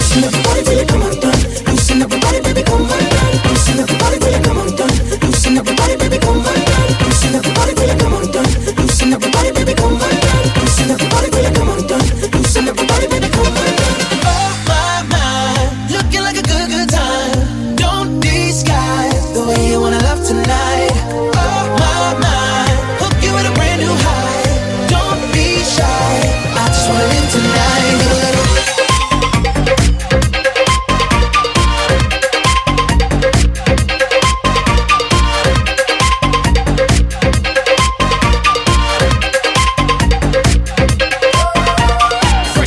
Genere: pop, deep, dance, house, club, successi, remix